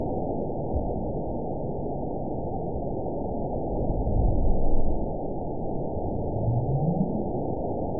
event 912505 date 03/27/22 time 23:49:55 GMT (3 years, 1 month ago) score 9.59 location TSS-AB04 detected by nrw target species NRW annotations +NRW Spectrogram: Frequency (kHz) vs. Time (s) audio not available .wav